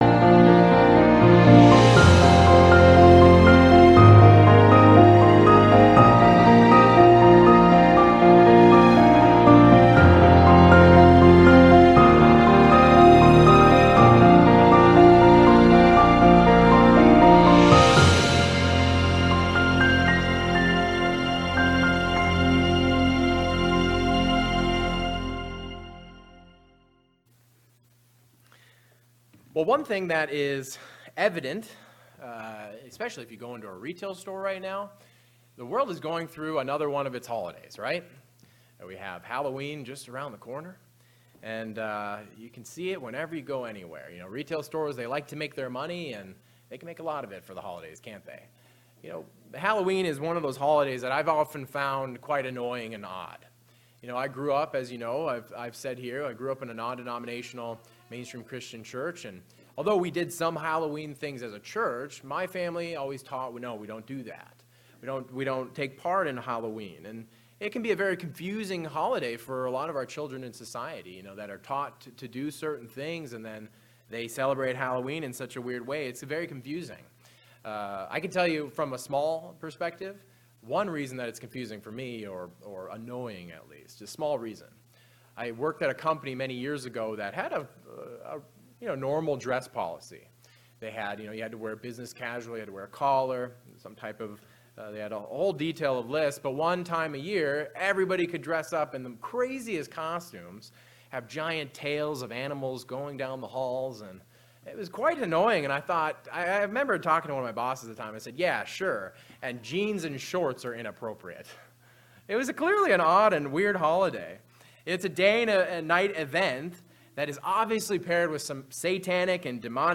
Given in Worcester, MA